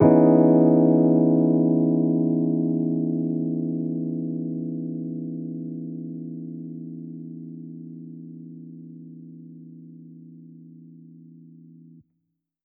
JK_ElPiano2_Chord-Emaj13.wav